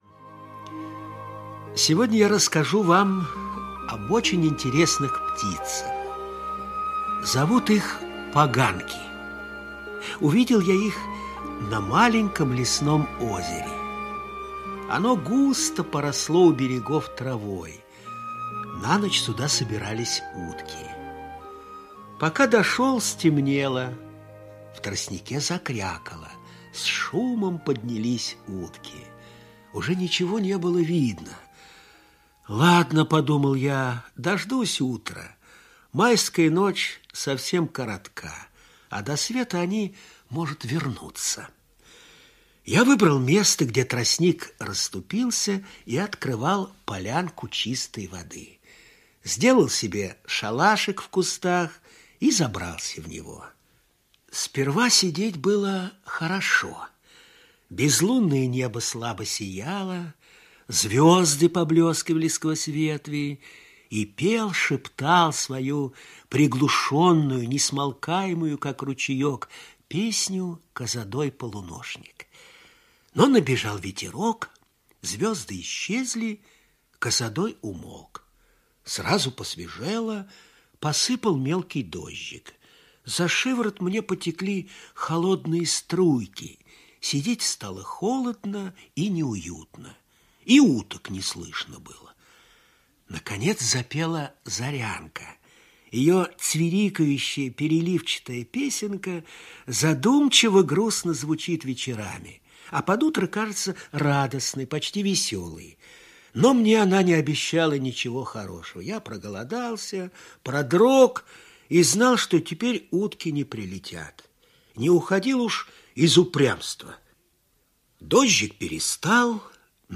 Аудиорассказ «Поганки»